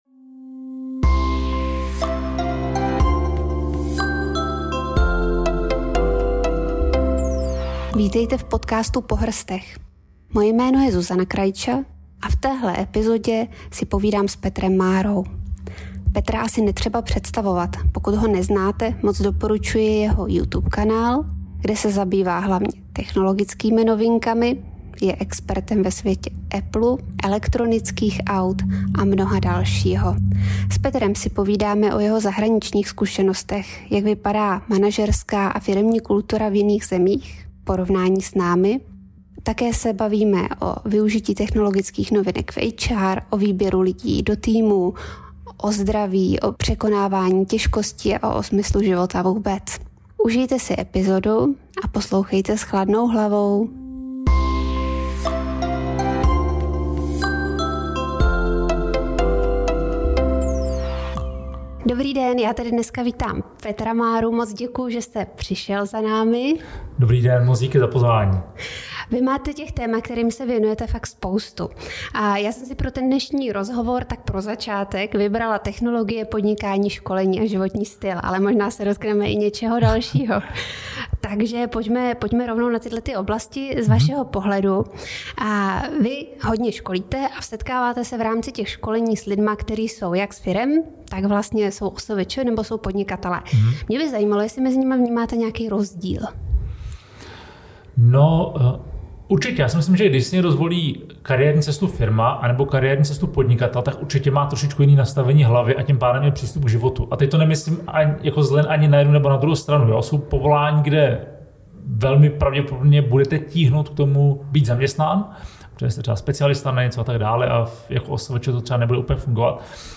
V rozhovoru si povídáme o jeho zahraničních zkušenostech, o využití technologie v HR, o zdraví či smyslu života.